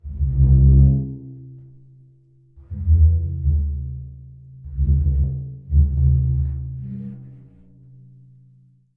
描述：我的萨满框架鼓打击乐声音效果
Tag: 韵律 帧鼓 萨满 敲击